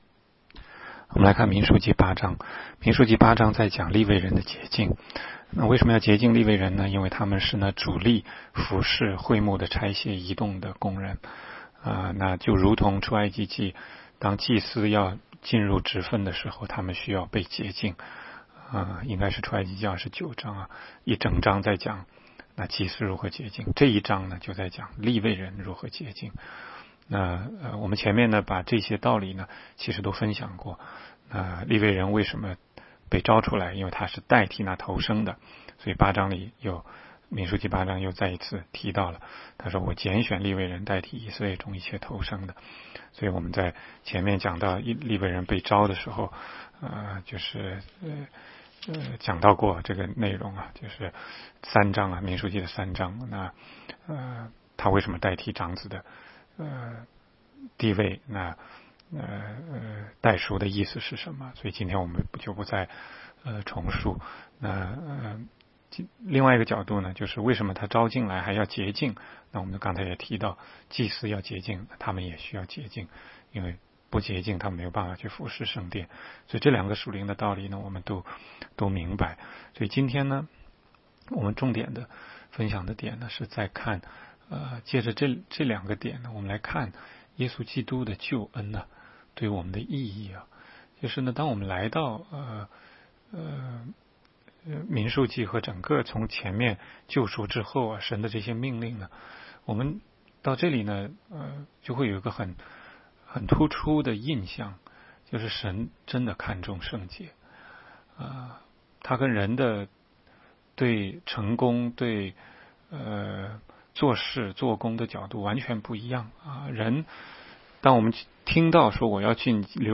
16街讲道录音 - 每日读经-《民数记》8章